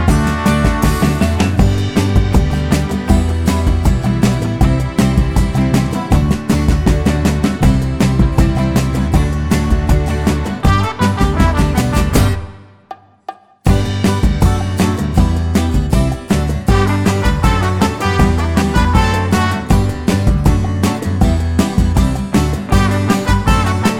no Backing Vocals Crooners 2:50 Buy £1.50